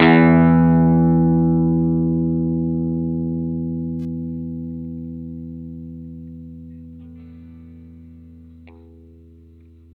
R12 NOTE  E.wav